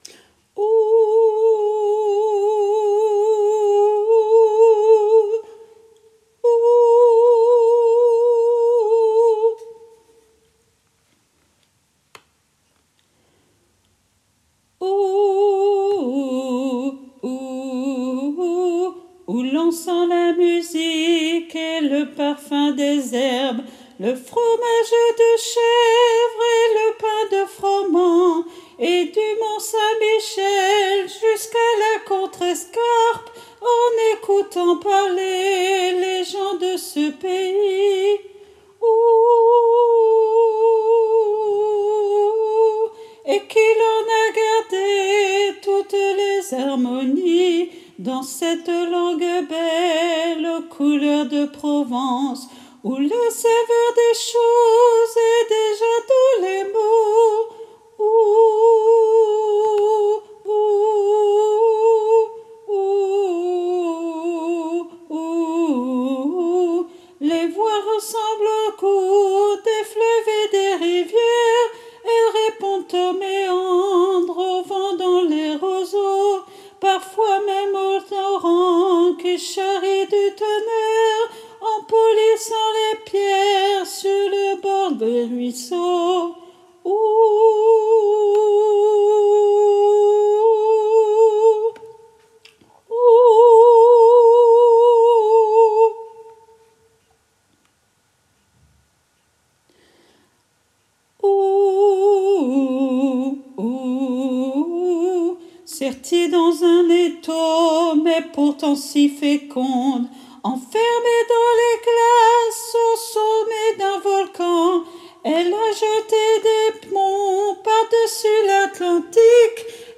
MP3 versions chantées
Hommes